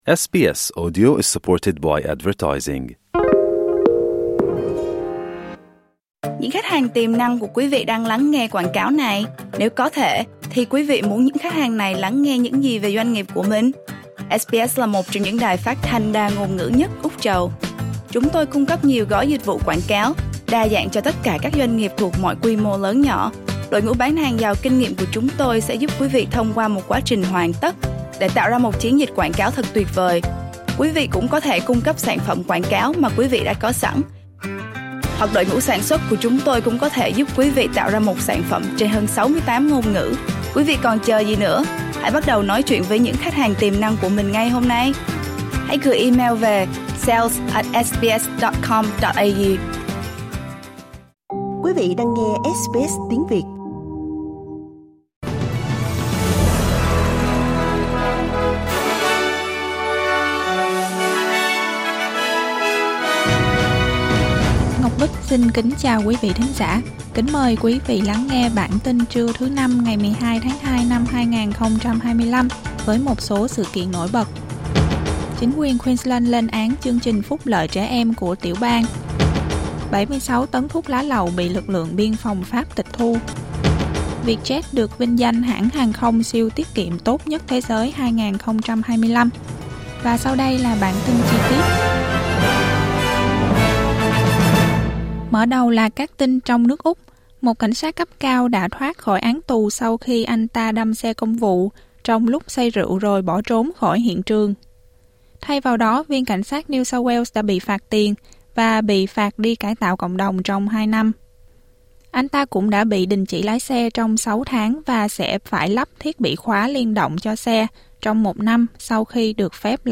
Vietnamese news bulletin Source: AAP